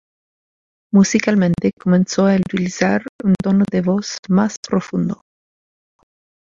Uitgesproken als (IPA)
/pɾoˈfundo/